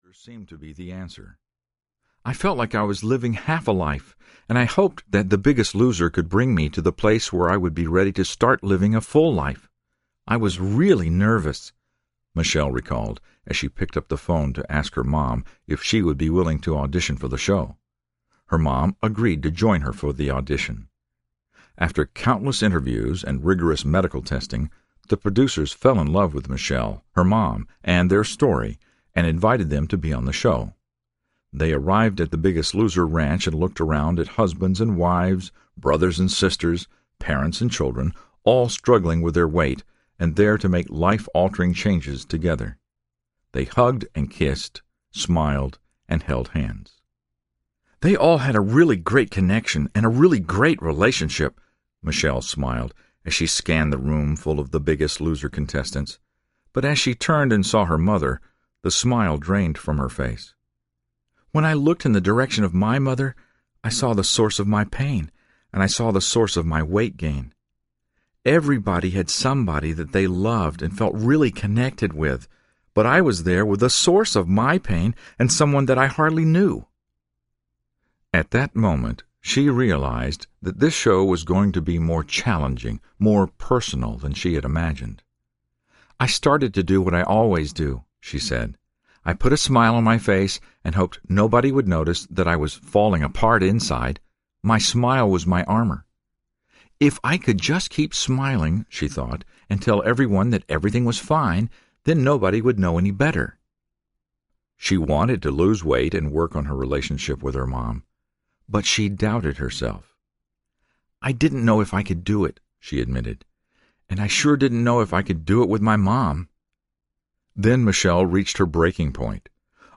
I Am Second Audiobook
4.85 Hrs. – Unabridged